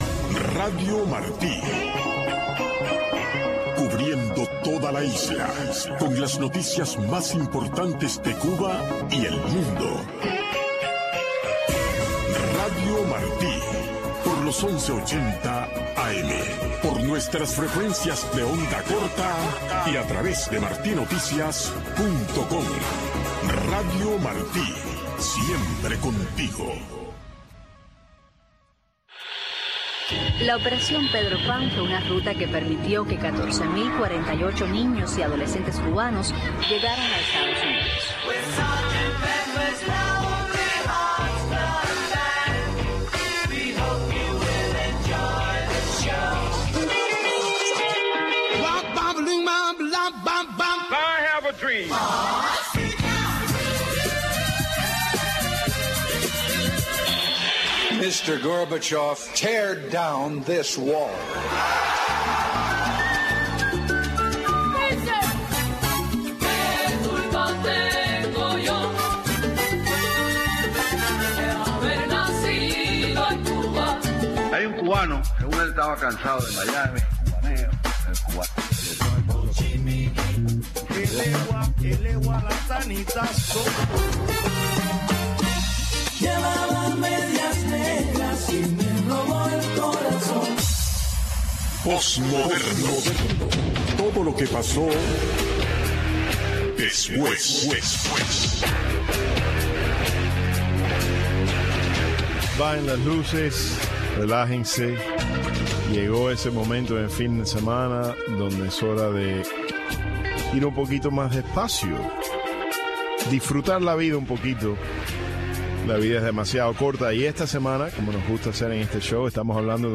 Postmoderno - Celebrando La Música New Wave